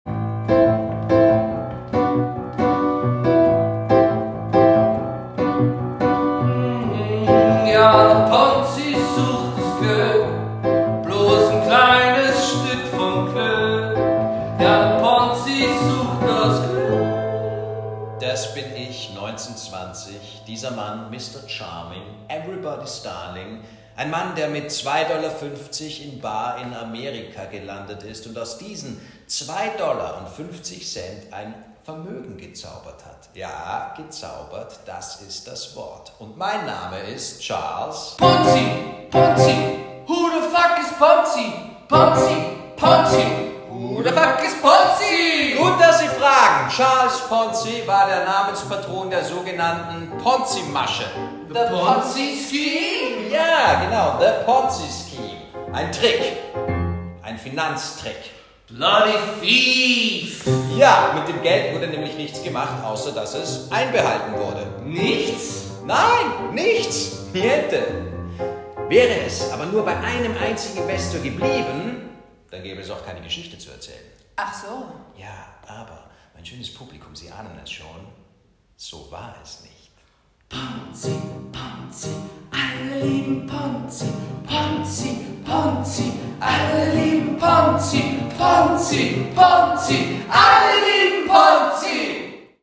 Musik. Licht.
Betrügergeschichte mit Gesang